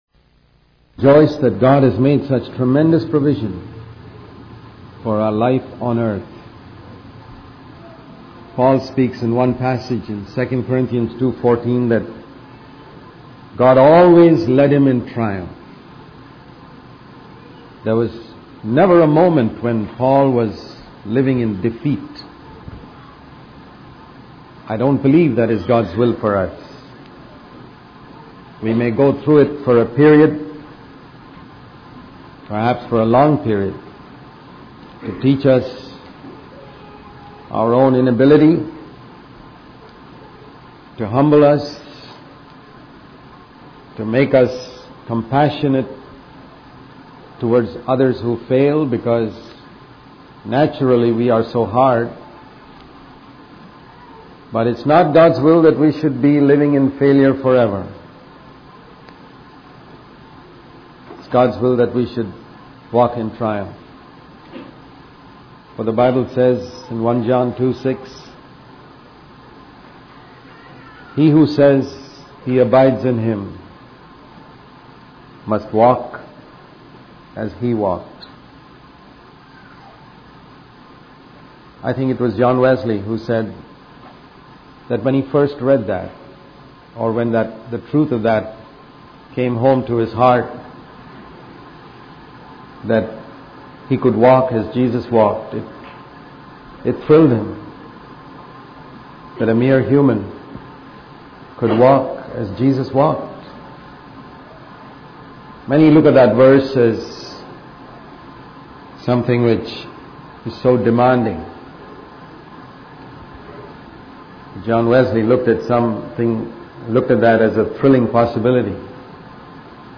In this sermon, the speaker emphasizes the importance of questioning and testing the teachings we encounter. He encourages listeners to examine whether the teachings align with God's word and the spirit of Christ.